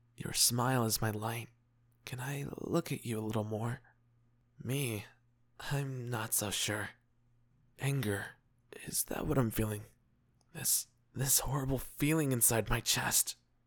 Voice: On the deep end, soft/gentle, and possibly slightly monotone.